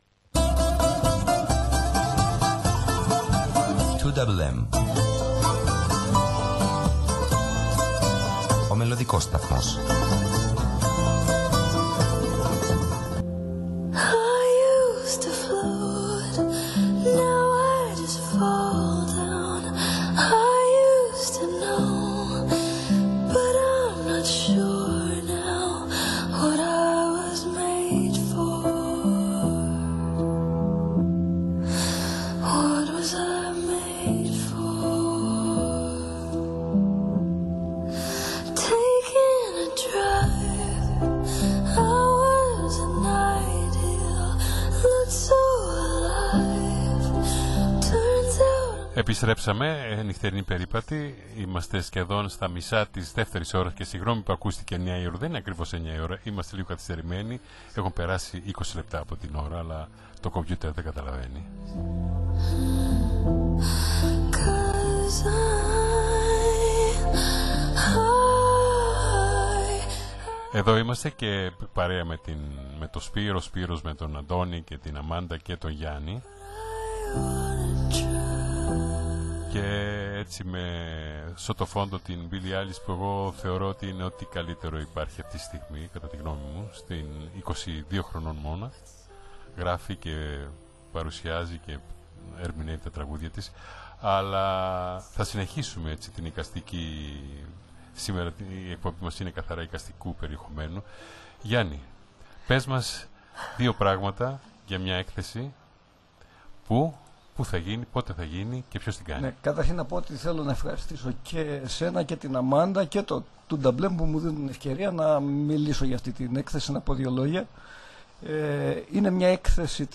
μίλησε σε ζωντανή σύνδεση